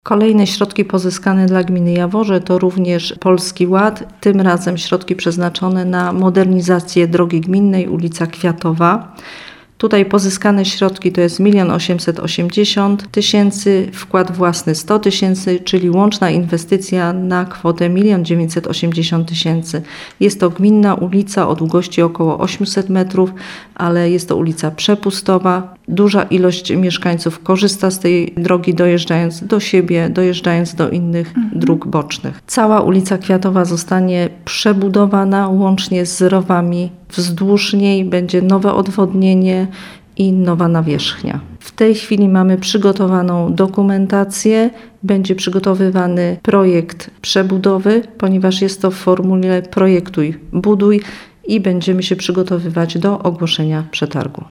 O inwestycji mówi Anna Skotnicka-Nędzka, pełniąca funkcję wójta gminy.